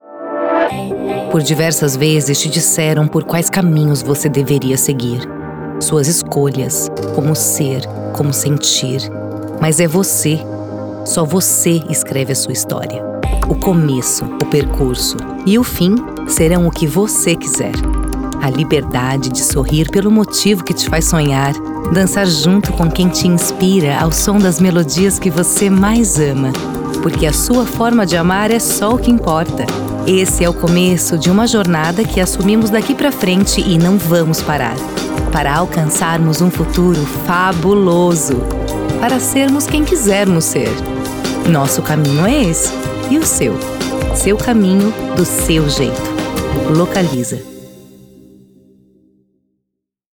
With a versatile, mature, and pleasant voice, I am able to adapt my narration style to the needs of the project, creating a unique and engaging experience for the listener.
With an excellent acoustic treatment system that offers excellent quality.
Demo-Comercial-Localiza-layout.wav